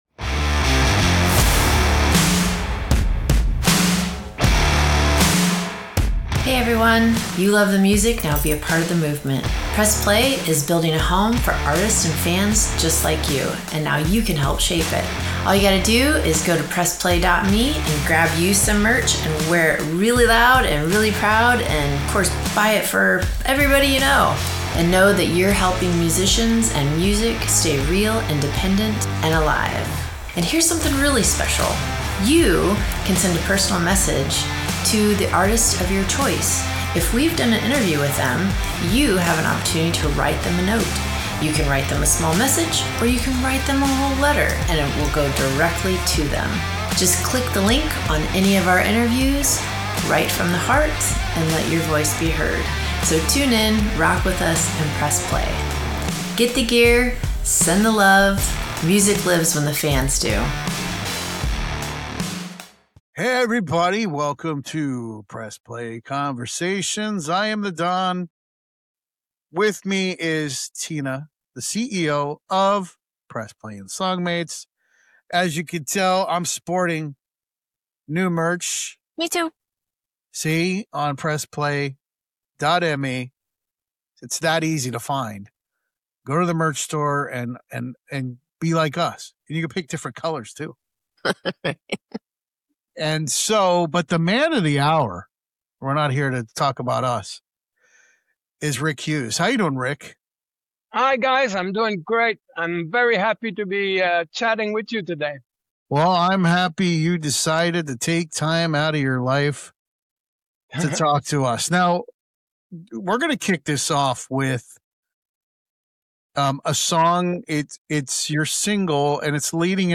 In this exclusive Press Play Radio interview, Rick opens up about his journey through the highs and lows of the industry, the grit behind the glory, and why his voice still echoes with purpose.